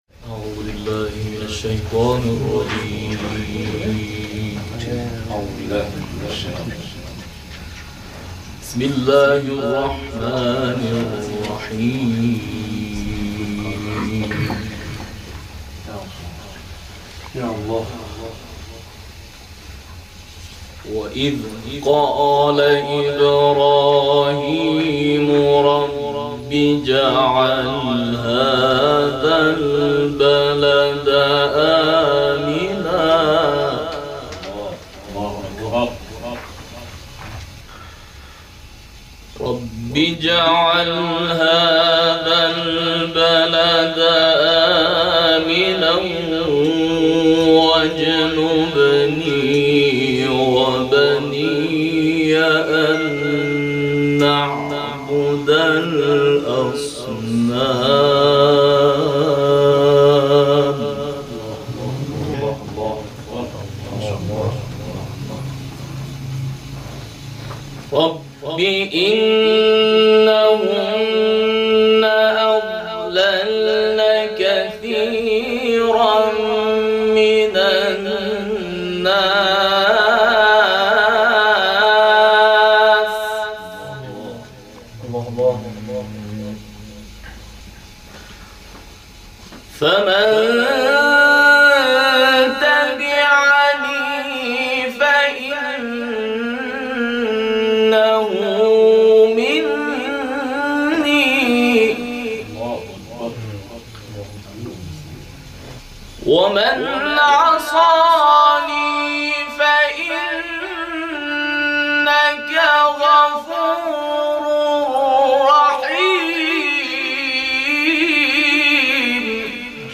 در جلسه نخبگان قرآنی مشهد
تلاوت